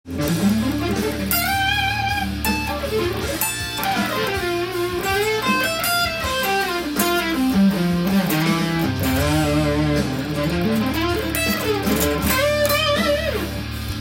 スィープピッキングは、右手のピックで
Dmのカラオケに合わせてスィープピッキングフレーズ入れると